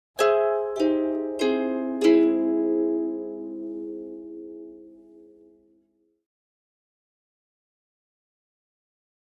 Harp, Descending Arpeggio In Two Voices, Type 4